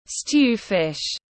Cá kho tiếng anh gọi là stew fish, phiên âm tiếng anh đọc là /stjuː fɪʃ/
Để đọc đúng cá kho trong tiếng anh rất đơn giản, các bạn chỉ cần nghe phát âm chuẩn của từ stew fish rồi nói theo là đọc được ngay.